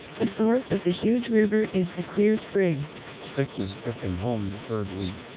Below, you can listen to short samples of noisy English speech at SNR = 10 dB processed by both vocoders.
(SNR=10dB)